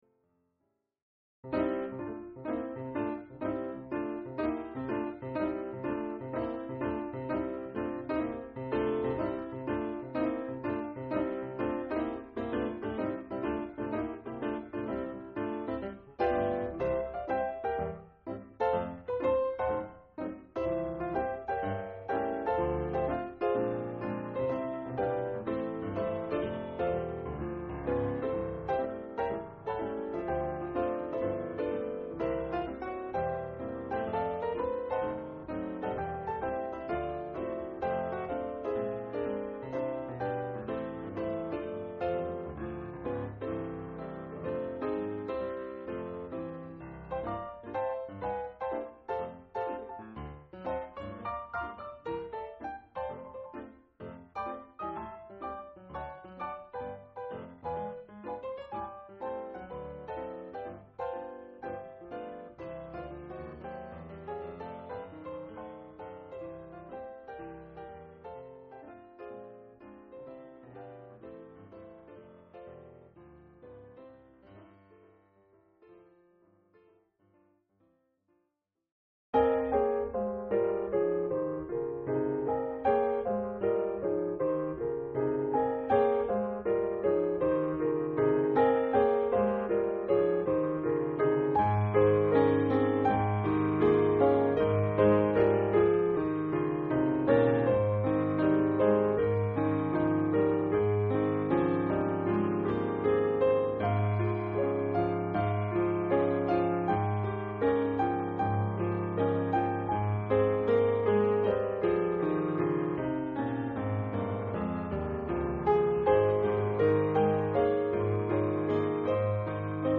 cool-jazz Christmas album